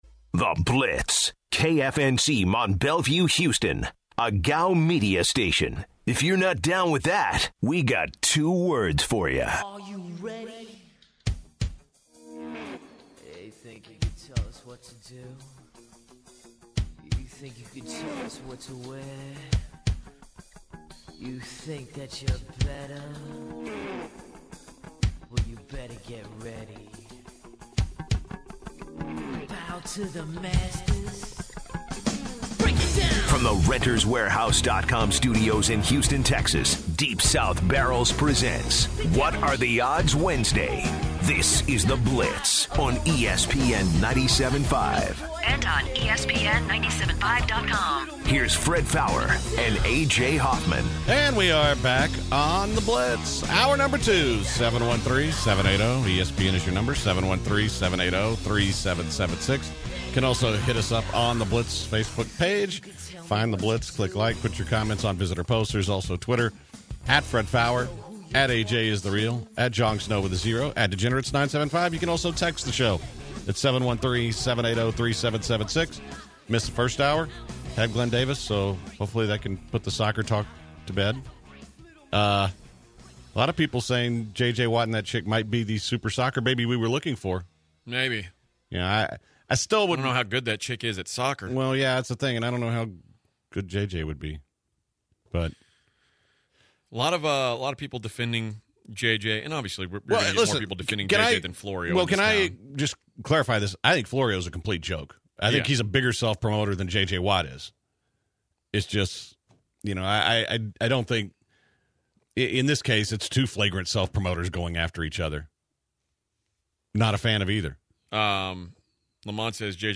Listeners call in with their “What are the Odds” to kick off the second hour of The Blitz.